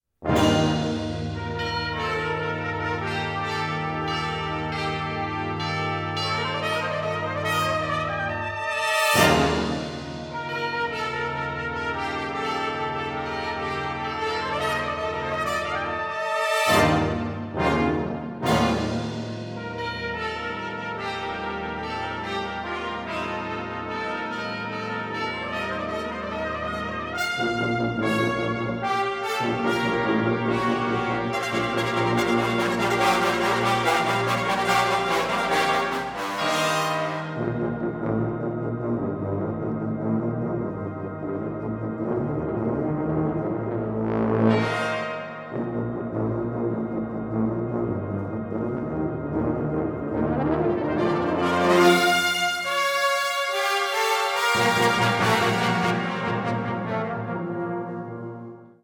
Nocturne for brass band